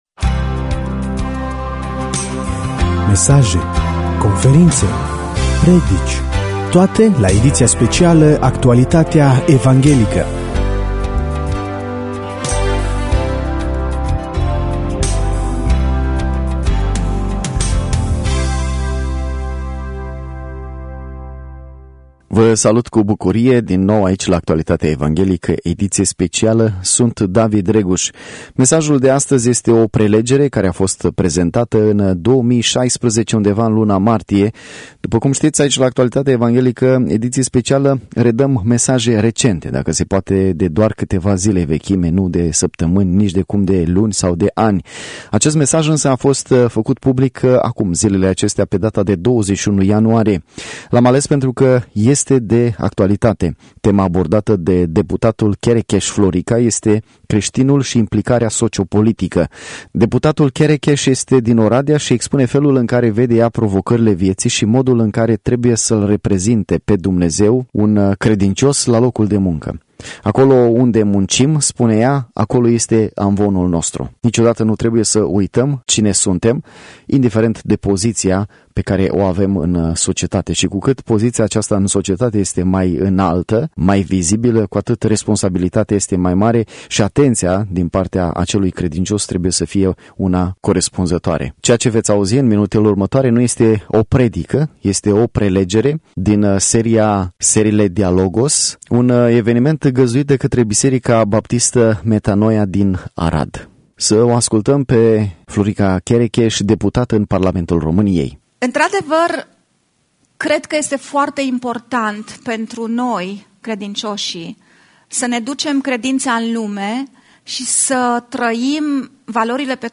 Luni pe 30 ianuarie am dat la RVE Suceava o prelegere a doamnei deputat Florica Cherecheş. Prelegerea a avut loc la Arad pe data de 20 martie 2016.